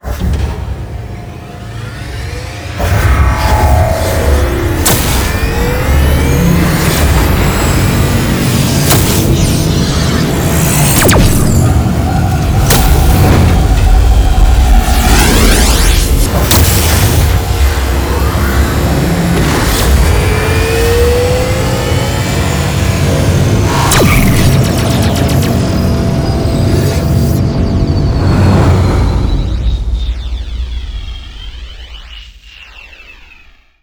wormhole.wav